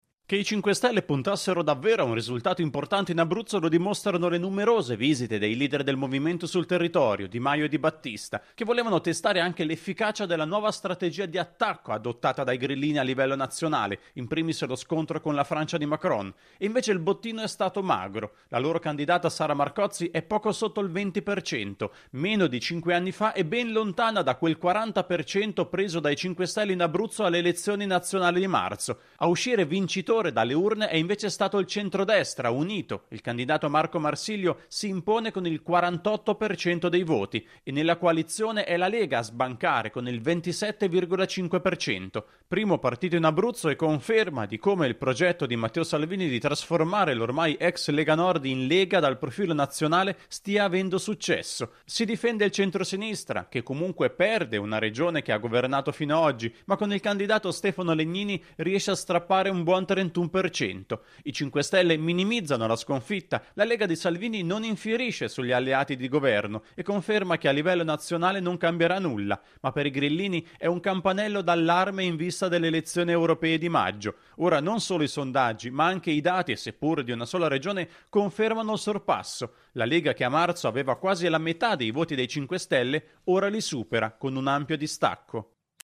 voto in Abruzzo - la corrispondenza